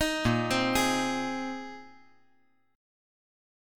A#9sus4 Chord
Listen to A#9sus4 strummed